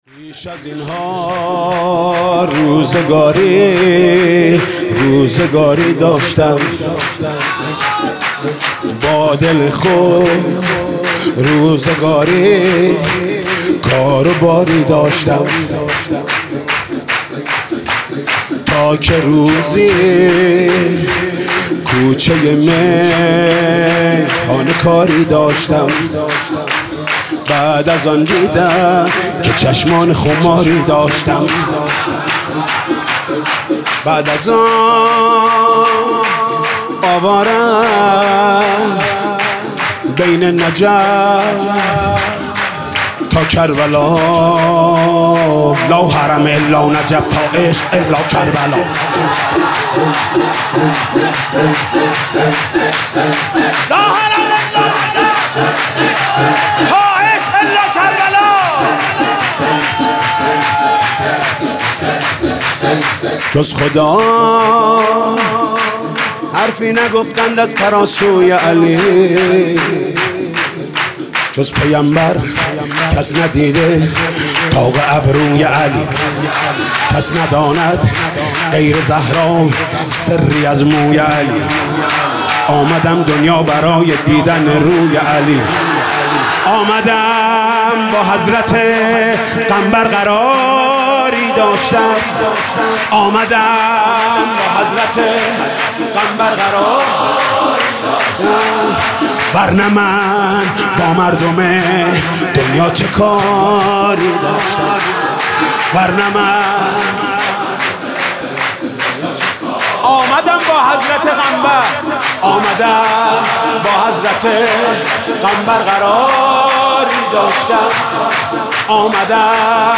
سرود: بیش از این ها روزگاری روزگاری داشتم